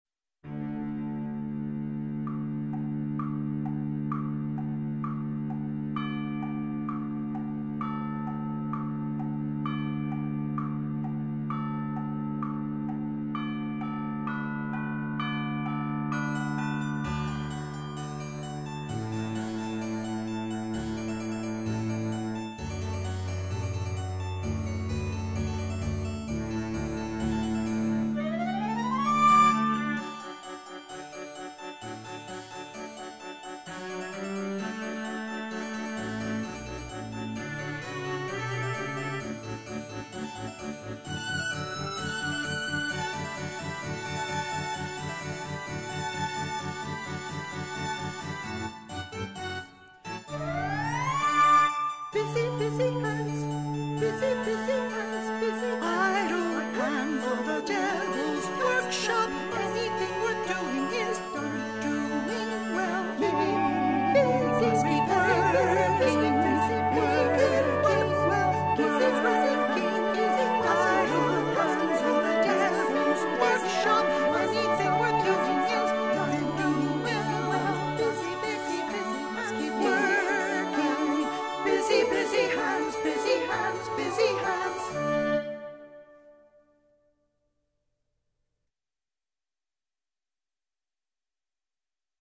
These recordings are rough demos.